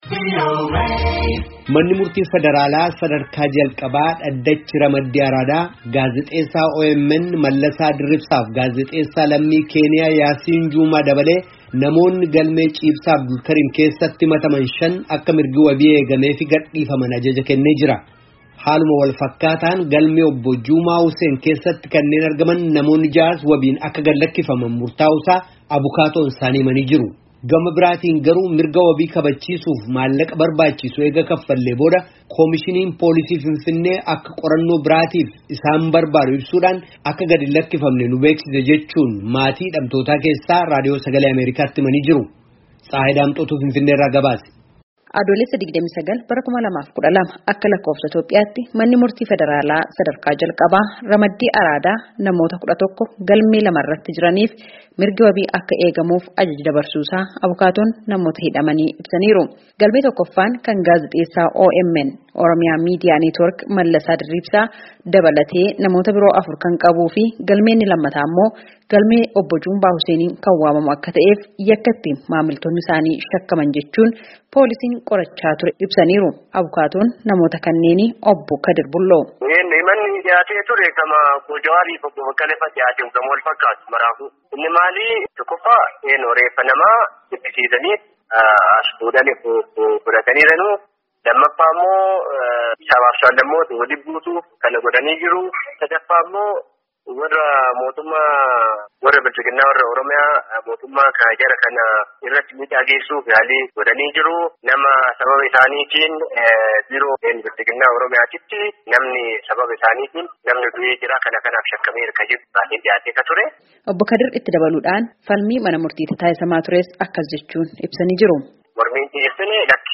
Finfinnee irraa gabaase.